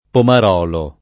[ pomar 0 lo ]